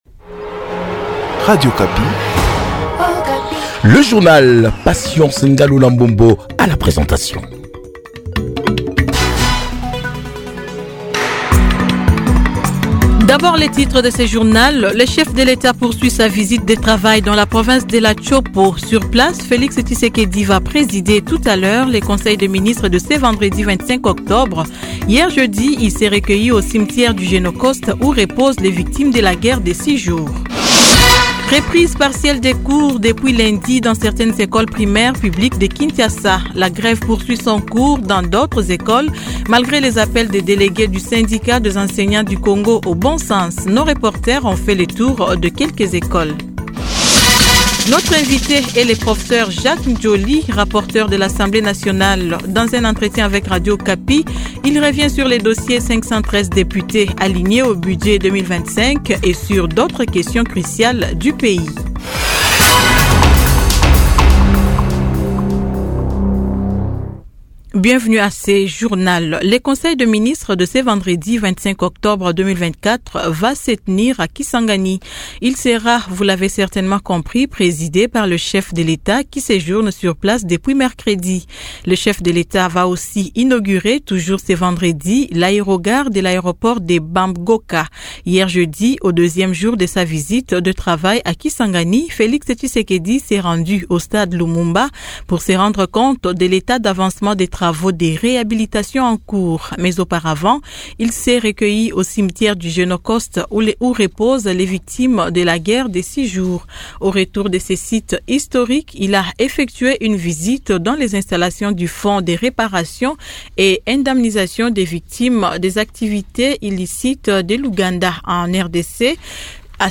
KINSHASA : Invité ; Professeur Jacques Djoli, rapporteur de l'Assemblée nationale.